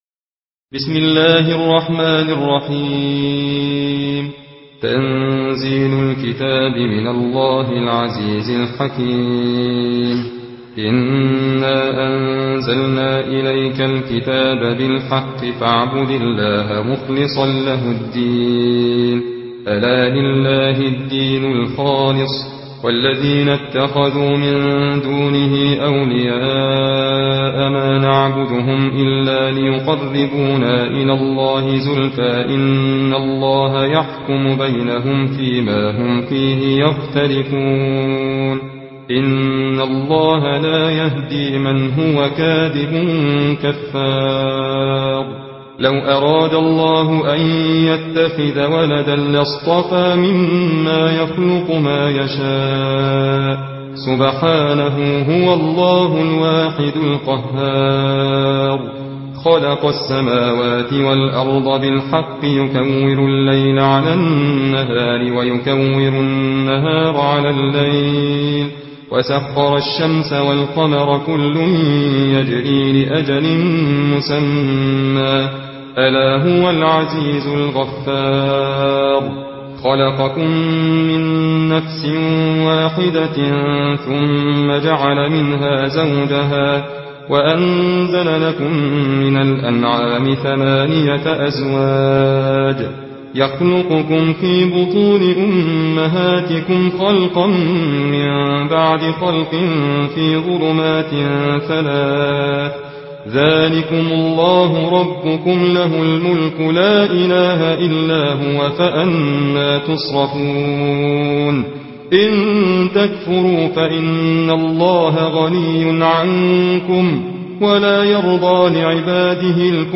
Riwayat Hafs an Assim